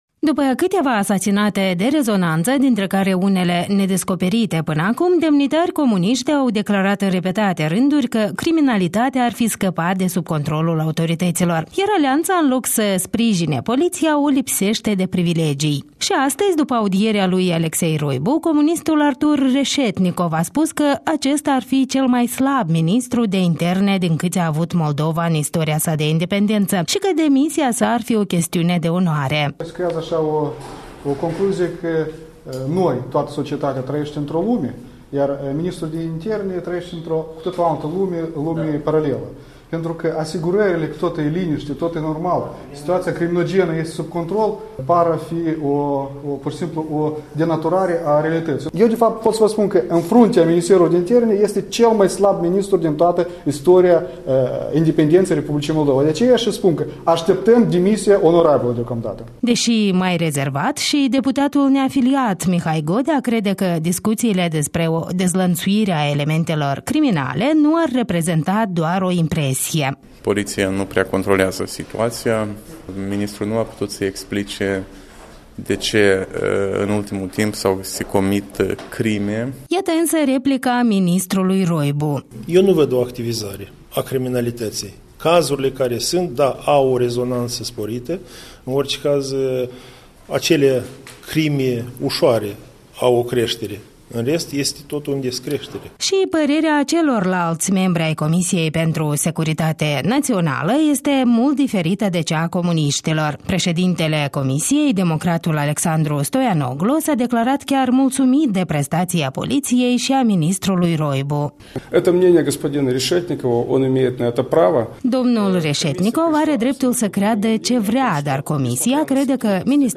Audierea ministrului de interne Alexei Roibu la Parlament